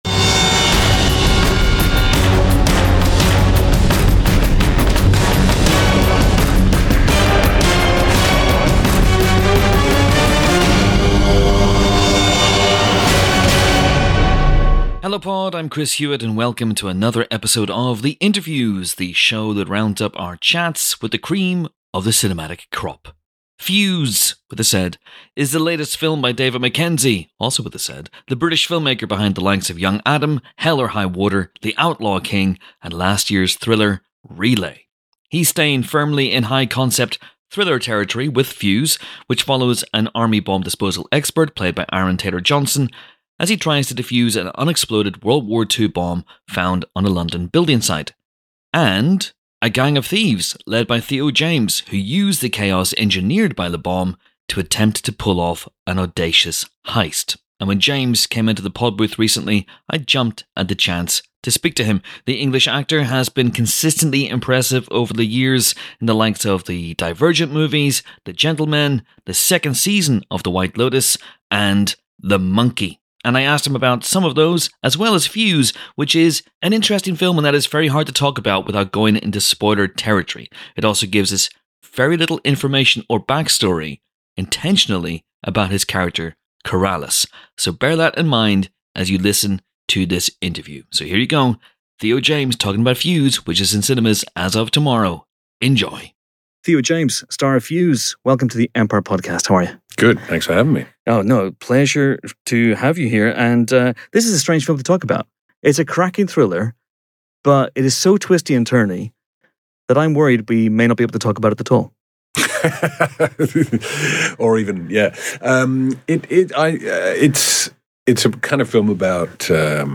[19:02 - 32:45] Finally, we have an extended excerpt from our Ready Or Not: Here I Come spoiler special interview [34:18 - 52:52 approx] with that film's directors, Matt Bettinelli-Olpin and Tyler Gillett, which features strong, unbleeped language from the off, as well as a deep dive into that movie's twists and turns.